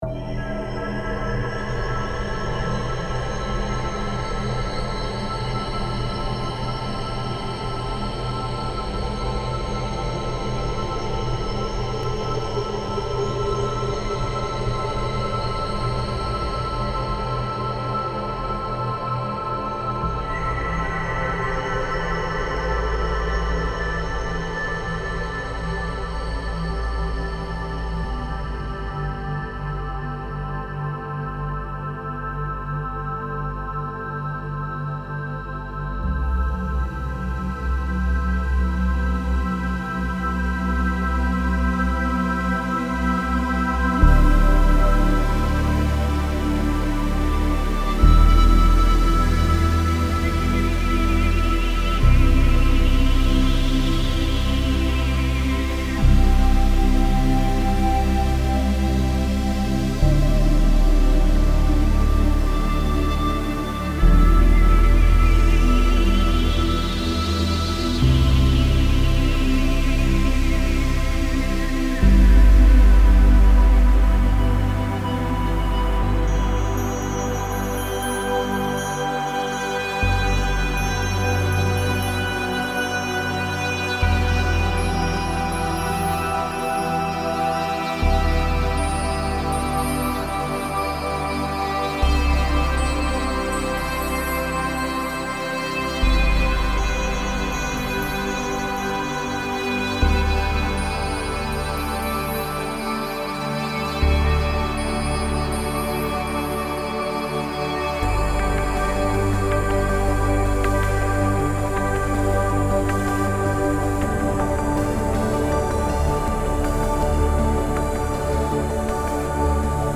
Ambient Chill Out / Lounge Cinematic / FX
STELLAR EVENT is an exploration of deep space ambience — 120 presets shaped by the energy of celestial collisions, cosmic waves, and interstellar mysteries. This collection for Reveal Sound Spire captures the low-end rumble of shifting planets, the ethereal glow of starlight, and the vast resonance of galaxies in motion.
From dark drones and seismic basses to glimmering pads, shimmering textures, and spectral plucks, each preset is designed to immerse you in the sound of the universe itself.